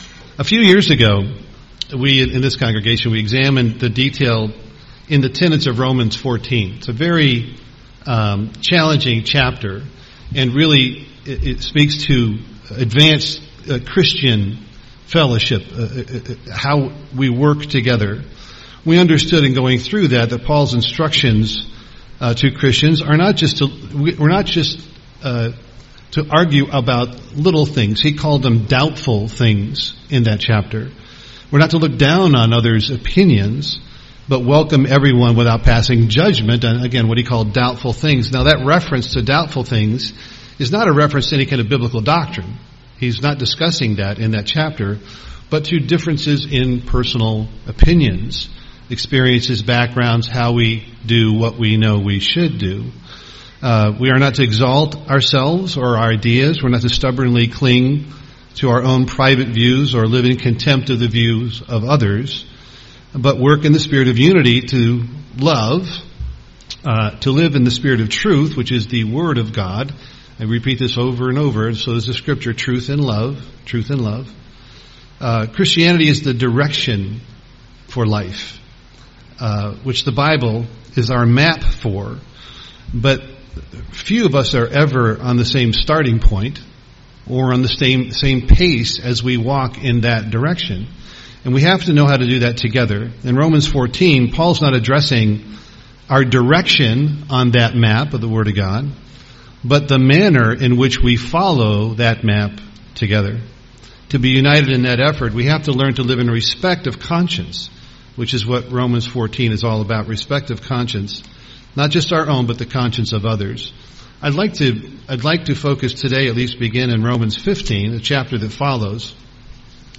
(Romans 12:13) UCG Sermon hospitality Studying the bible?